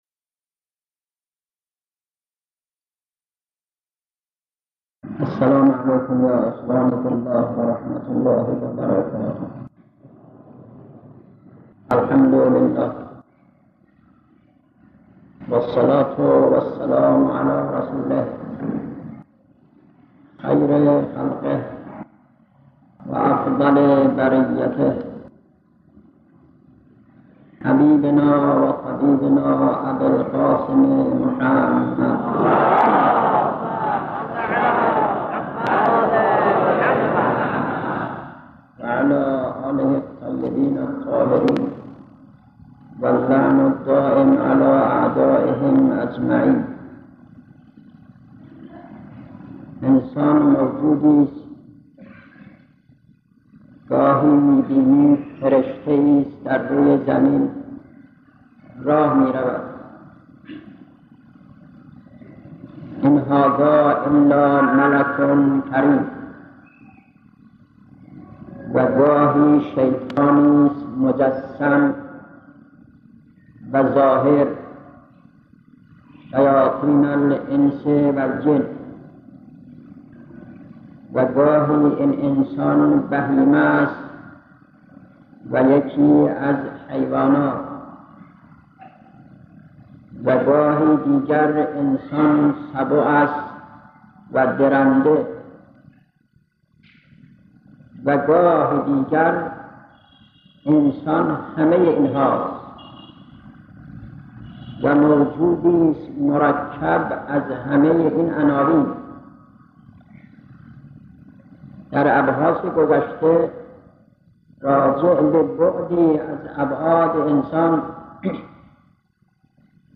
درس اخلاق - 145 جلسه - آیت الله مشکینی — پایگاه دانلود مذهبی و فرهنگی زهرامدیا